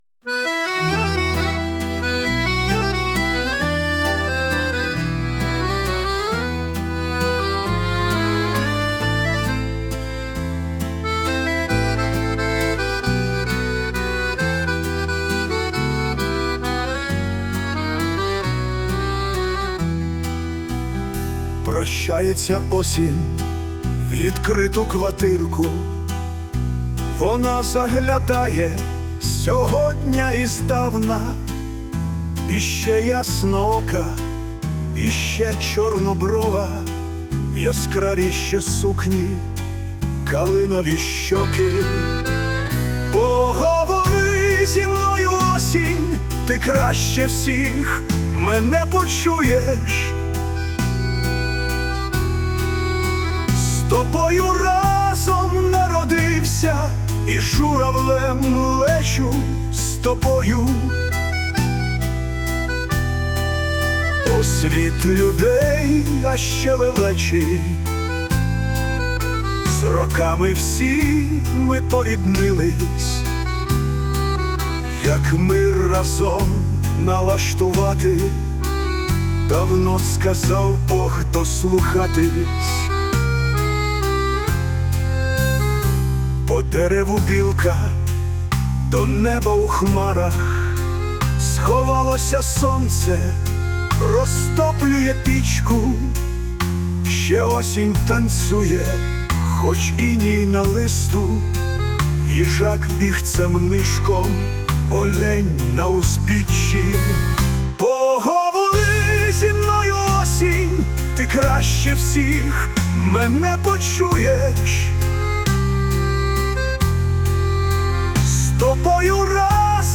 Мелодія на слова пісні:
СТИЛЬОВІ ЖАНРИ: Ліричний